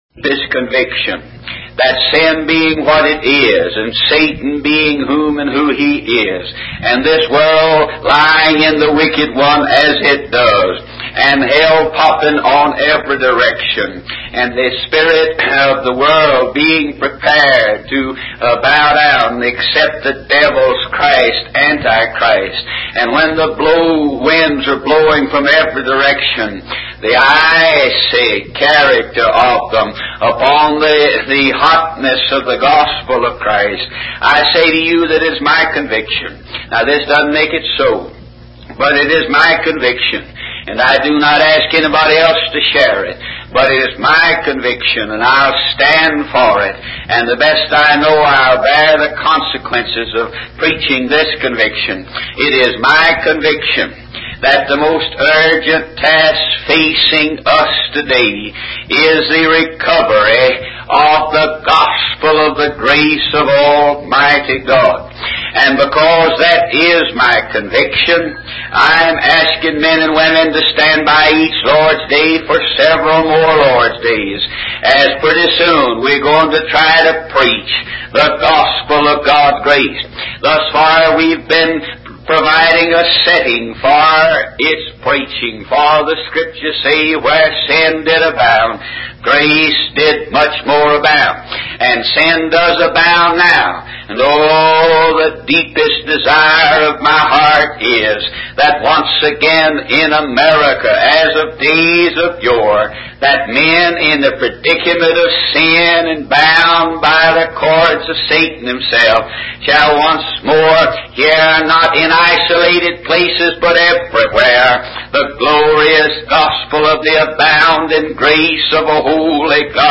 In this sermon, the preacher emphasizes the need to recover the true gospel of God's grace as preached by the Apostle Paul. He criticizes the popular preaching of the day, which he believes limits the work and love of Christ.